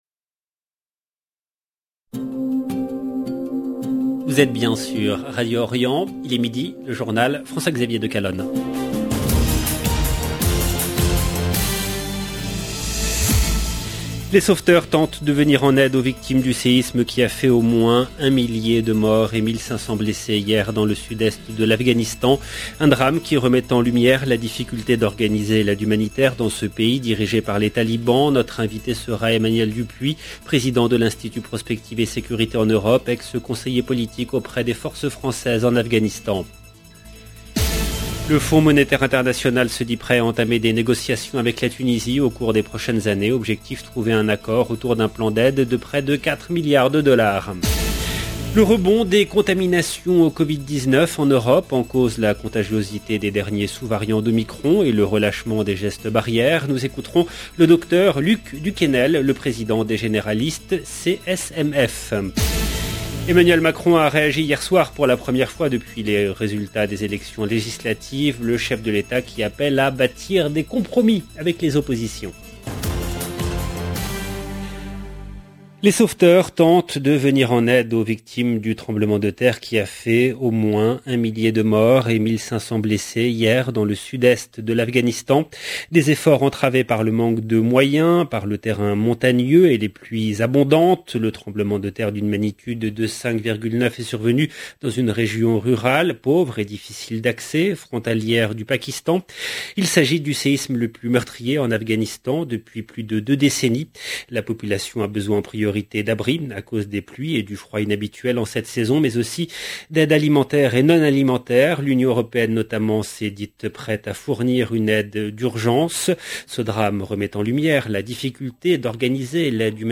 EDITION DU JOURNAL DE 12 H EN LANGUE FRANCAISE DU 23/6/2022